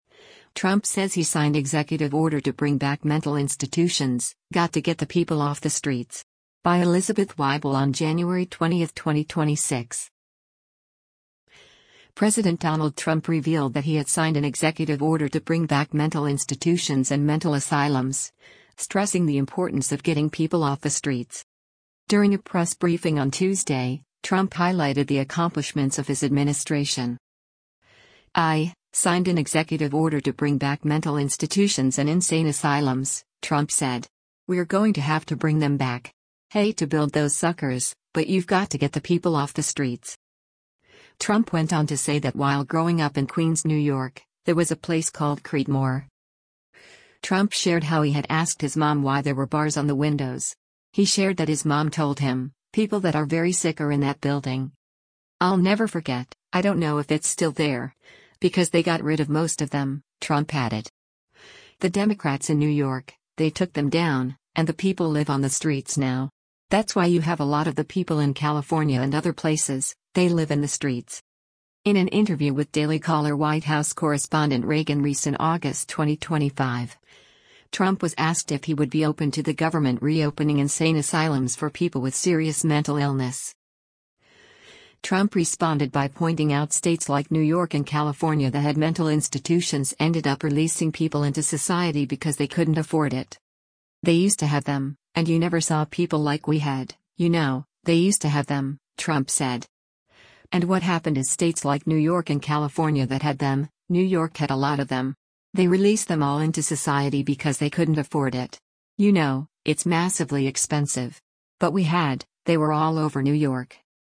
During a press briefing on Tuesday, Trump highlighted the accomplishments of his administration.